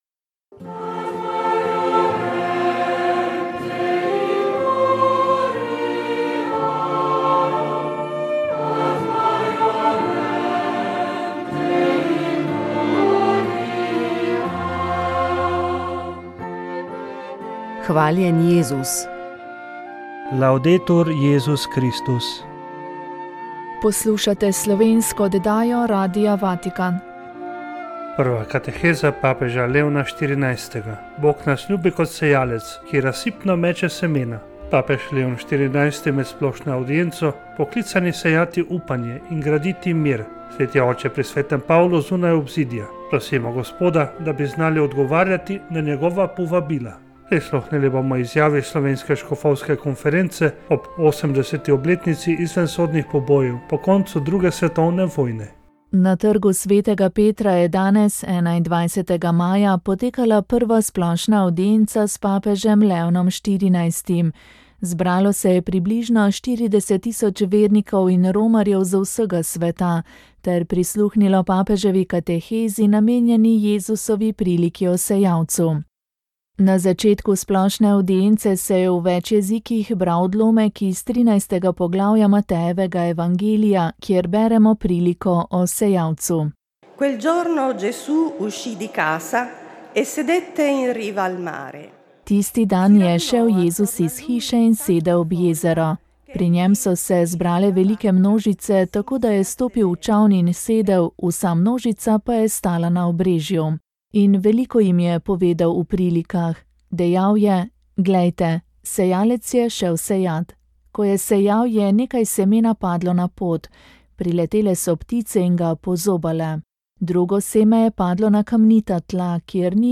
Študijski Center za narodno spravo je pripravil znanstveni posvet z naslovom Misel o samostojni in neodvisni Sloveniji med slovensko emigracijo. Na posvetu je sodelovalo osem strokovnjakov, ki so predstavljali različne vidike omenjene tematike. V oddaji Moja zgodba ste tokrat slišali prvi posnetek.